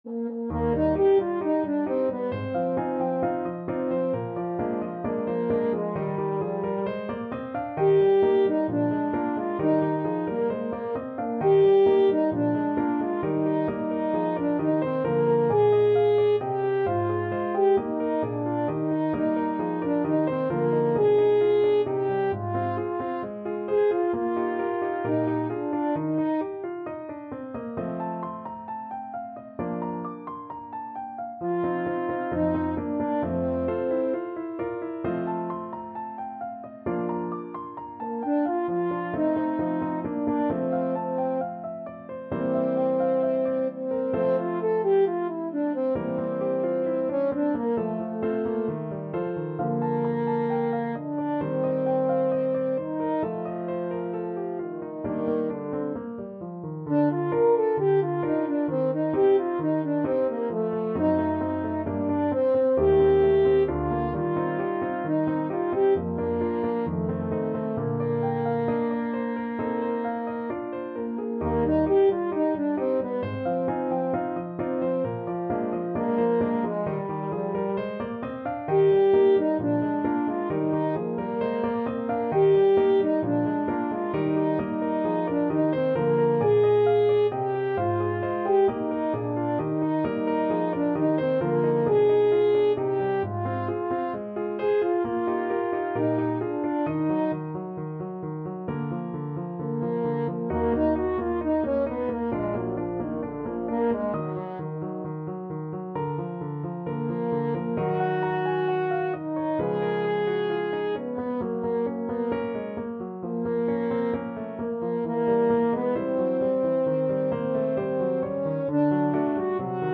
French Horn
Eb major (Sounding Pitch) Bb major (French Horn in F) (View more Eb major Music for French Horn )
2/2 (View more 2/2 Music)
=132 Allegro assai (View more music marked Allegro)
Classical (View more Classical French Horn Music)